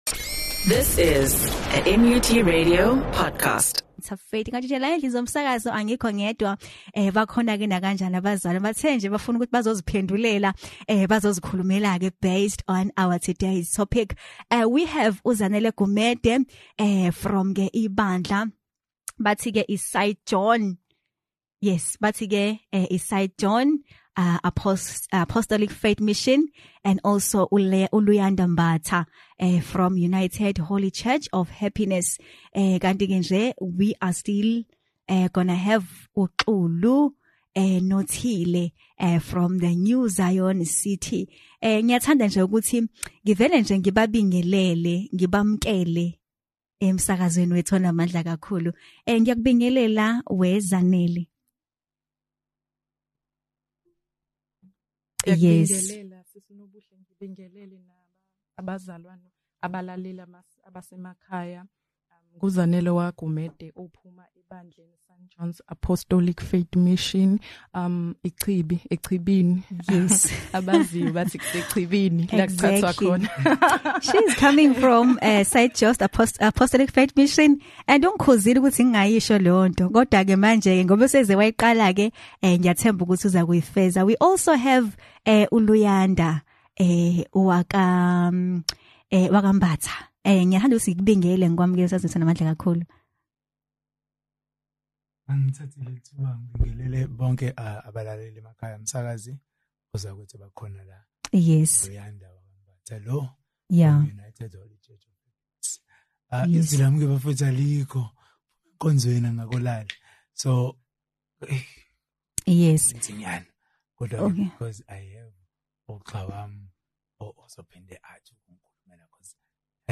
The discussion was based on different perspective, as you can see brethren from different religions..